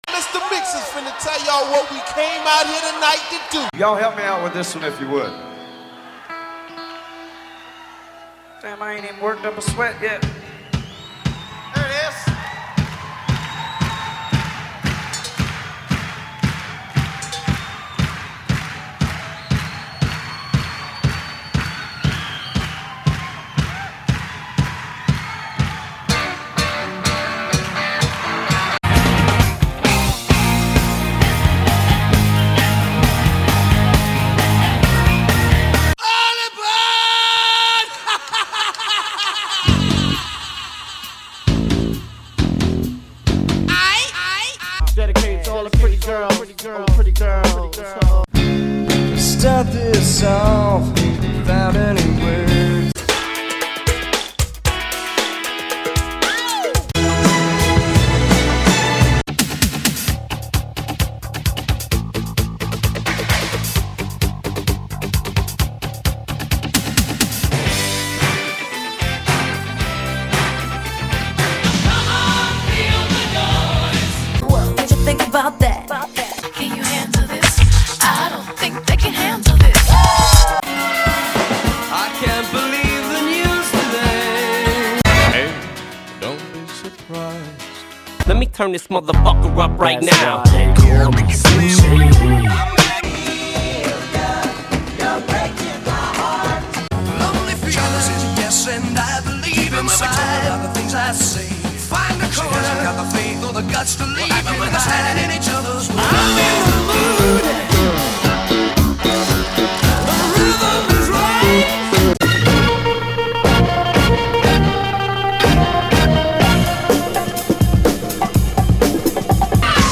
Like the Milgram experiment, which showed that ordinary citizens of New Haven could administer deadly electric shocks to total strangers, my experiment in slicing and splicing hundreds of soundbites of popular music together into themed songs shows that ordinary humans cannot endure the flood of memories that are triggered by the abrupt succession of highly familiar sound clips.
Armed with Quicktime and the ability to cut and past bits of music together, as well as a lot of free time on nights and weekends, I organized hundres of bits of music into some coherent order, lyrics-wise and beat-wise.